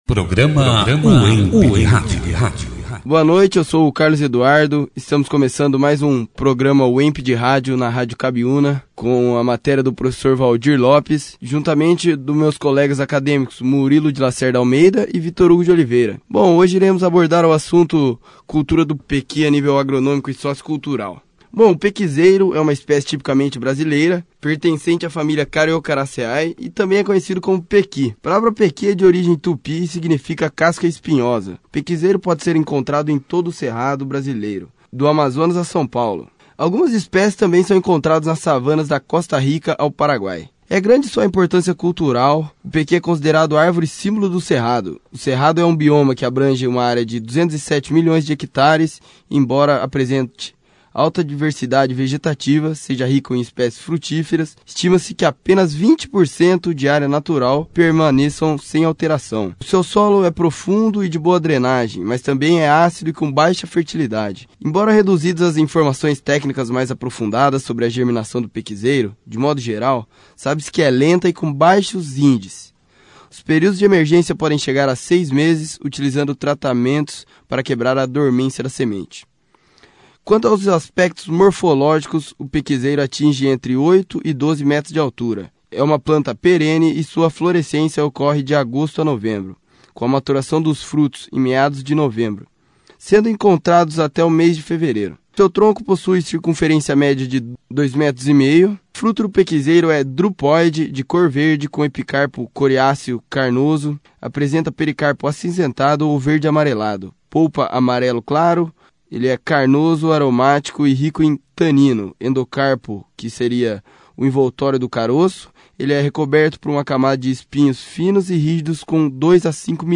Produzido e apresentado pelos alunos, Acadêmicos do 4º ano do curso de Agronomia